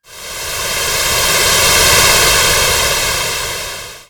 GasReleasing10.wav